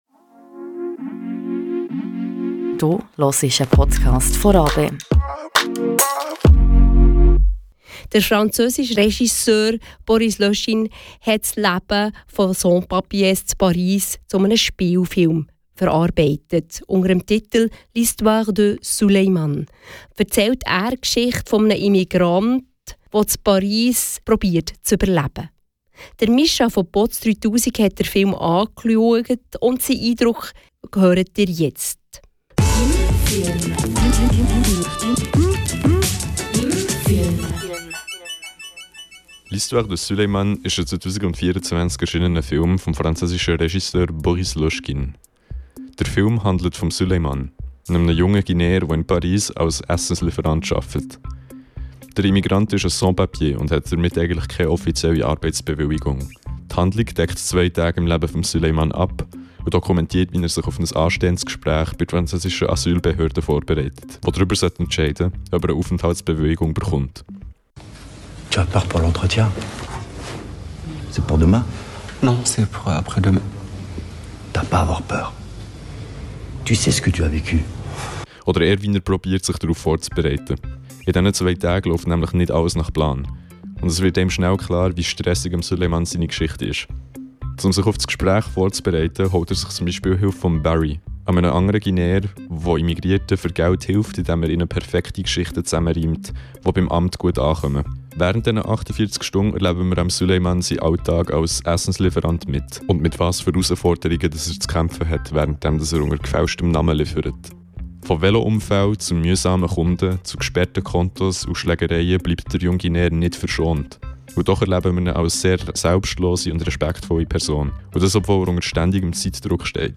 Eine Filmreview zum Film "L'Histoire de Souleymane"